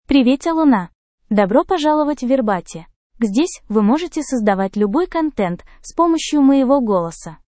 Luna — Female Russian AI voice
Luna is a female AI voice for Russian (Russia).
Voice sample
Listen to Luna's female Russian voice.
Female